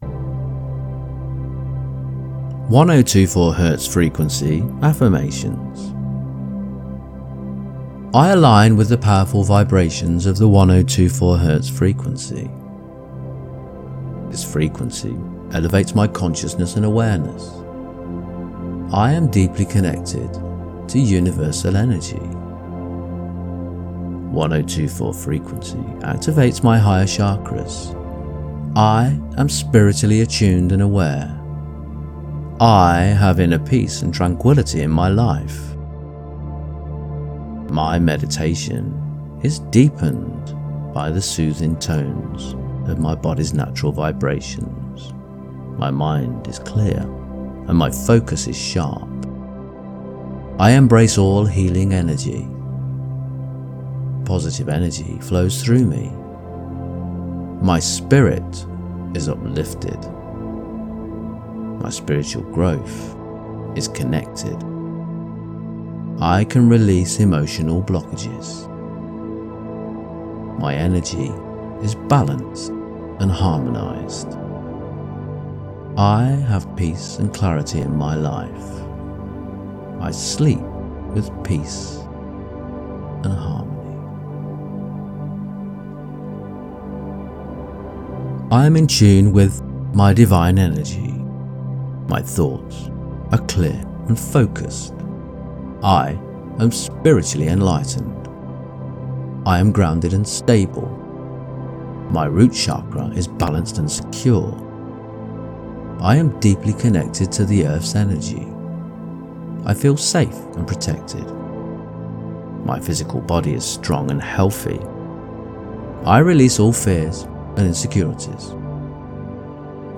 General 1024 Hz Affirmations:
1024-affirmations.mp3